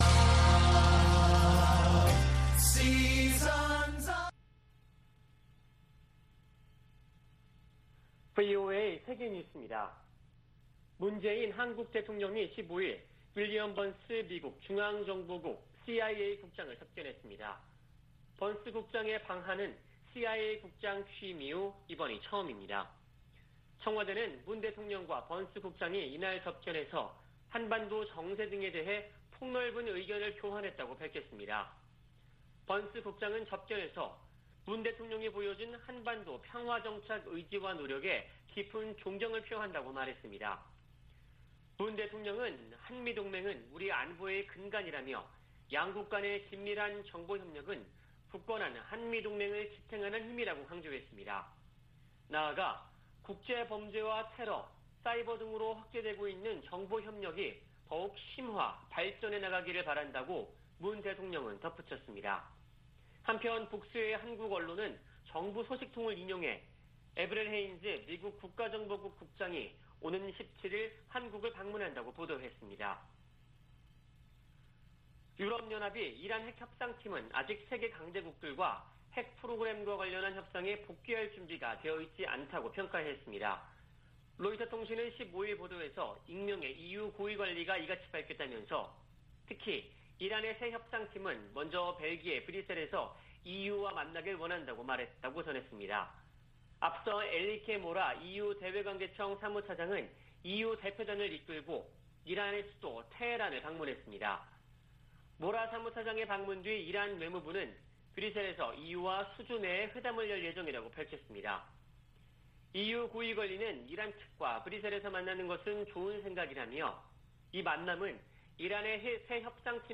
VOA 한국어 아침 뉴스 프로그램 '워싱턴 뉴스 광장' 2021년 10월 16일 방송입니다. 미국이 탈퇴 3년 만에 유엔 인권이사회 이사국으로 선출됐습니다. 미 국무부가 북한 핵 문제 해결을 위해 동맹과 활발한 외교를 펼치고 있다고 밝혔습니다. 미국과 한국 등 30여개국이 세계적으로 증가하는 랜섬웨어 공격 대응에 적극 공조하기로 했습니다.